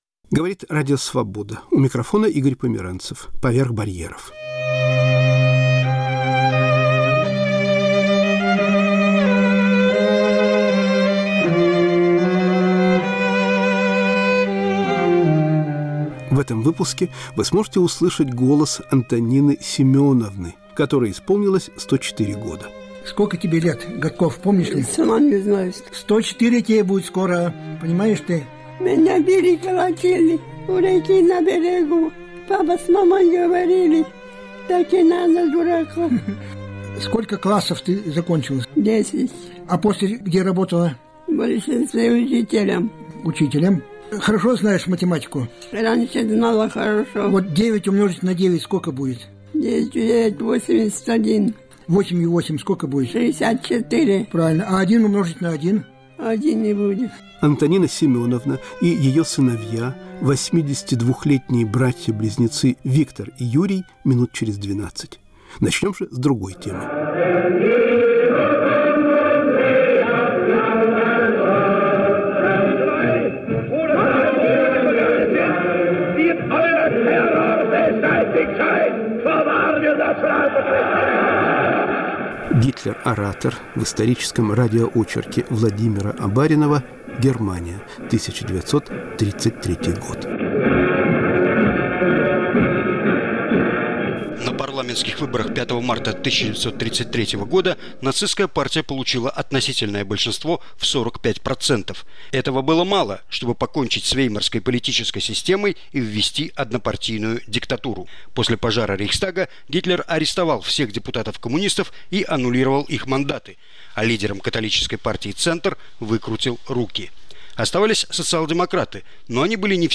Исторический радиоочерк.